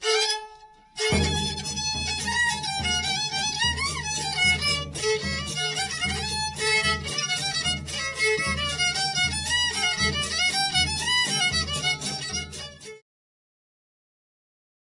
Tę wypowiedź przyjęliśmy jako motto dla płyty prezentującej najstarsze archiwalne nagrania ludowej muzyki skrzypcowej ze zbiorów Instytutu Sztuki.